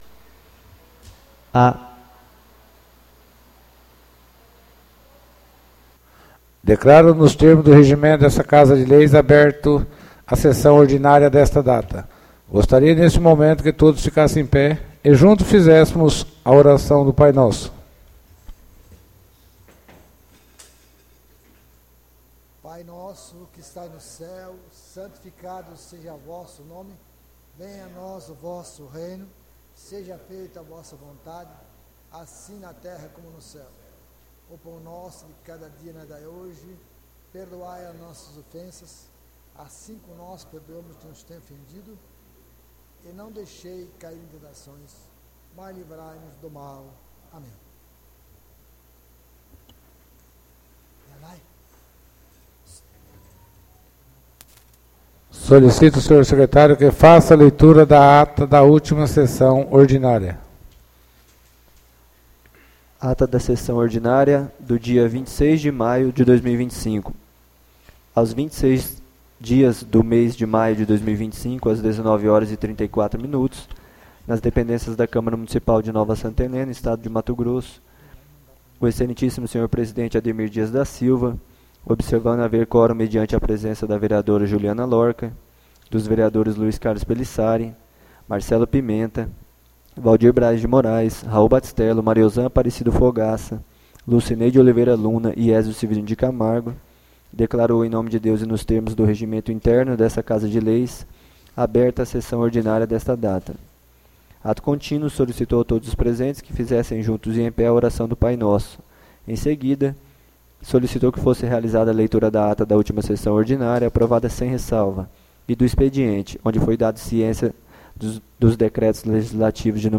ÁUDIO SESSÃO 02-06-25 — CÂMARA MUNICIPAL DE NOVA SANTA HELENA - MT